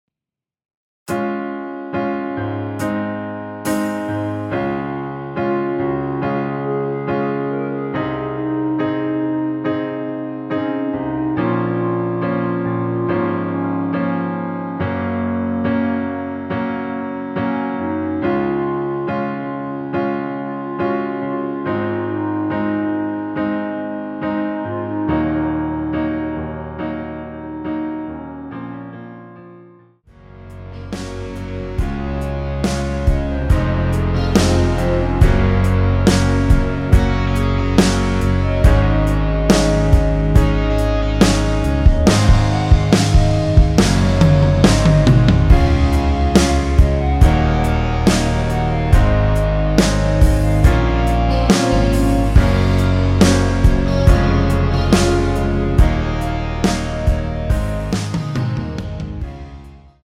노래하기 편하게 전주 1마디 만들어 놓았습니다.(미리듣기 확인)
원키에서(-1)내린 멜로디 포함된 (1절+후렴)으로 진행되는 MR입니다.
Db
앞부분30초, 뒷부분30초씩 편집해서 올려 드리고 있습니다.
(멜로디 MR)은 가이드 멜로디가 포함된 MR 입니다.